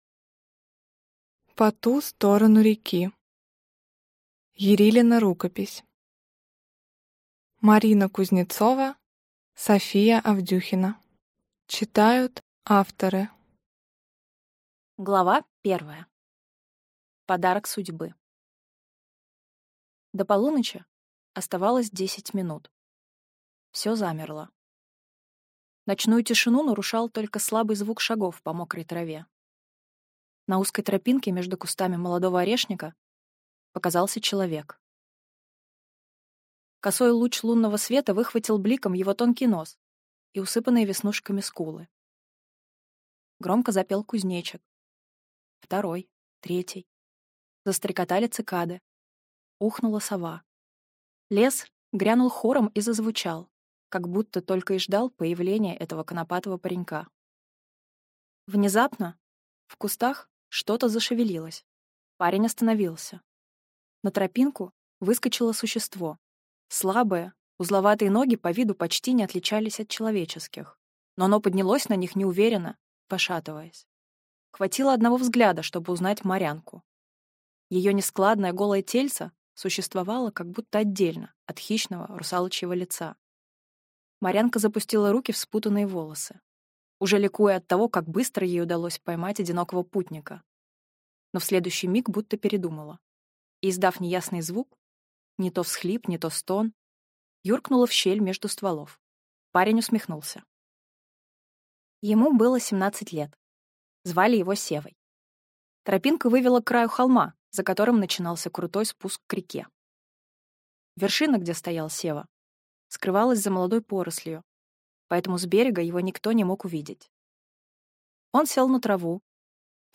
Аудиокнига Ярилина рукопись | Библиотека аудиокниг